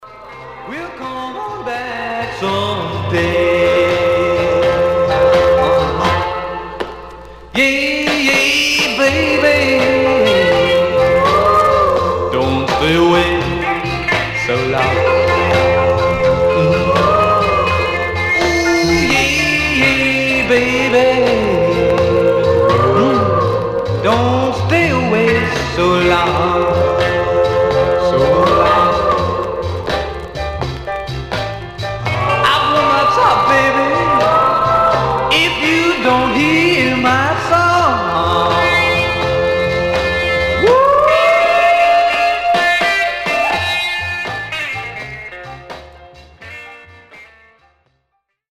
Surface noise/wear Stereo/mono Mono
Teen